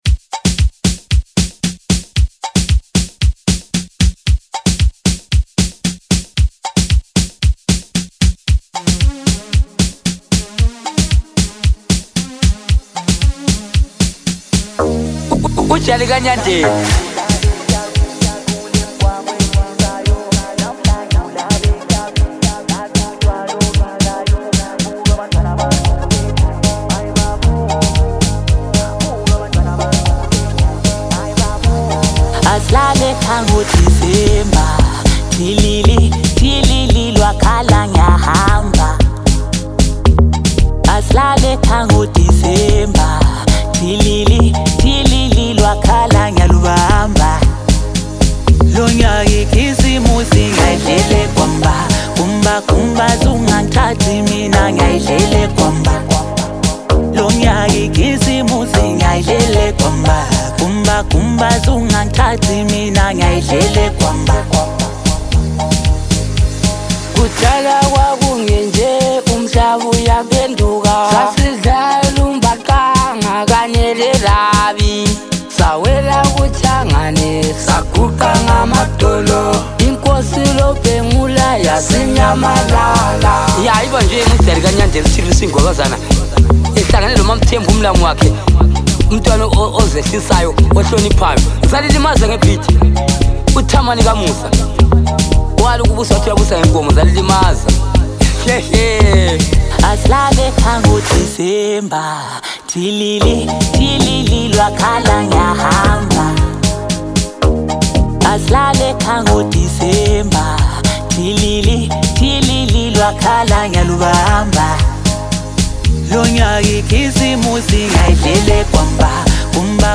Korean singer and songwriter